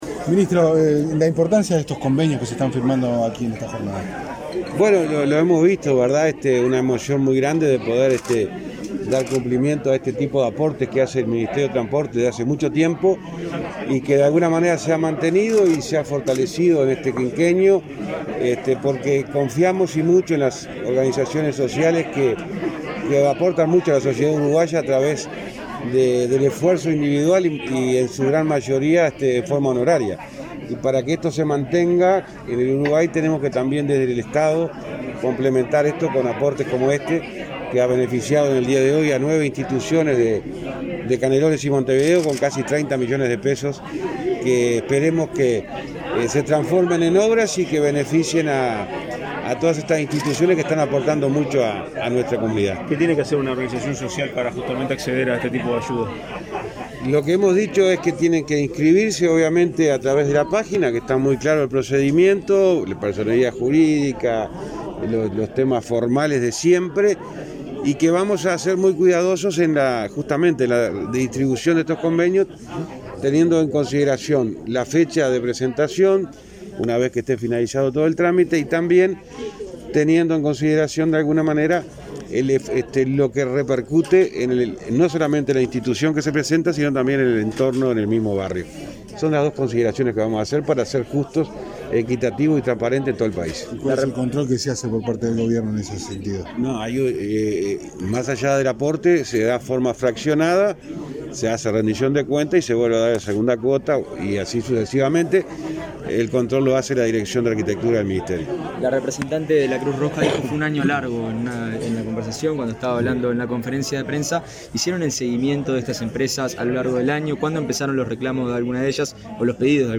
Declaraciones a la prensa del ministro de Transporte y Obras Públicas, José Luis Falero